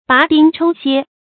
拔丁抽楔 bá dīng chōu xiē
拔丁抽楔发音
成语注音 ㄅㄚˊ ㄉㄧㄥ ㄔㄡ ㄒㄧㄝ